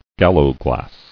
[gal·low·glass]